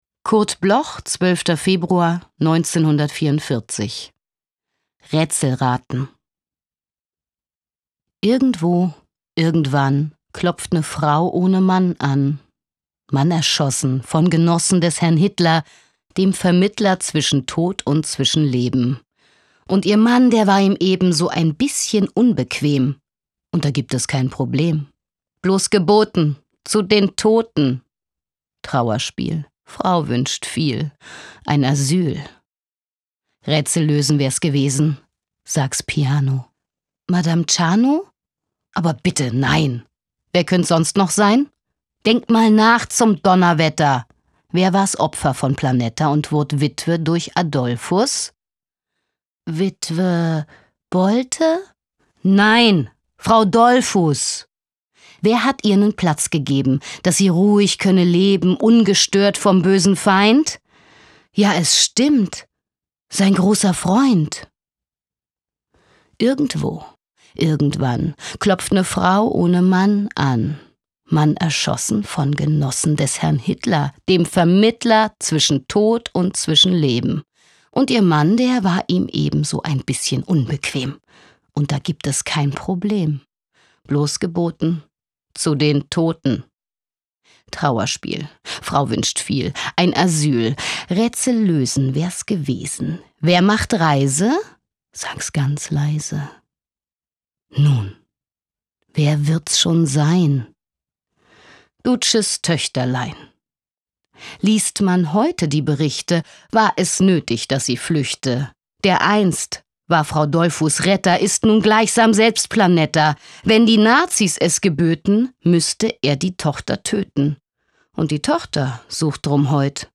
Aufnahme: speak low, Berlin · Bearbeitung: Kristen & Schmidt, Wiesbaden